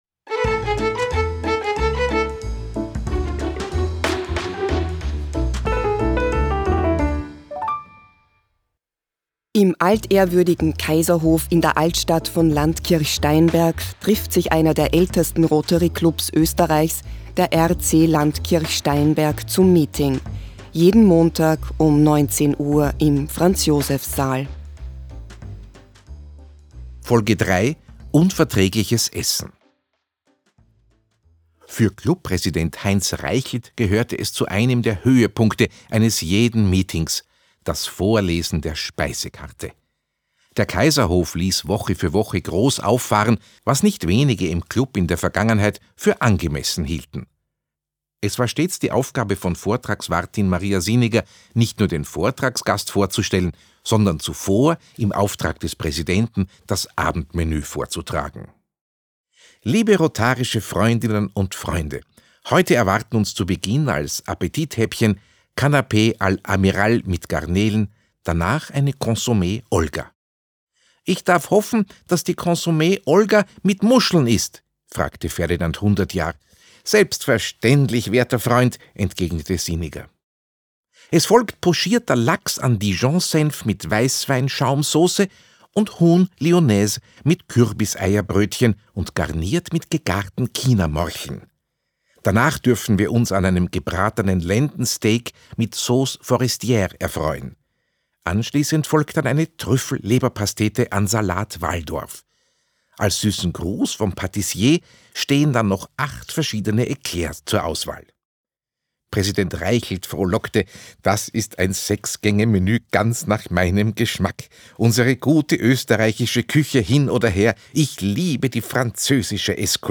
Audio-Comedy